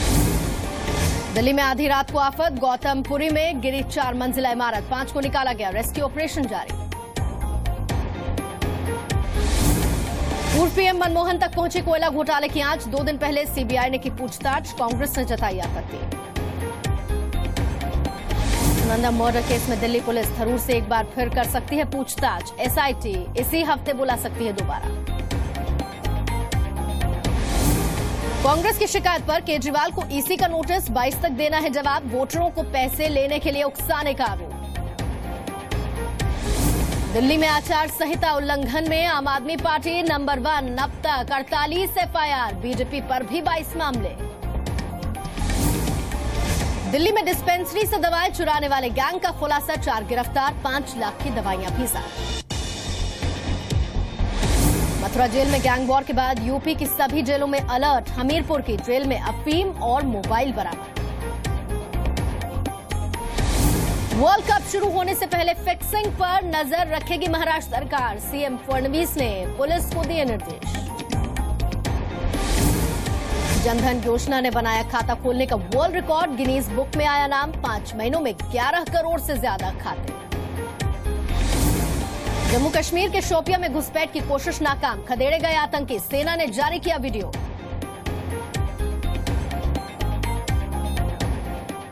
Headlines of the day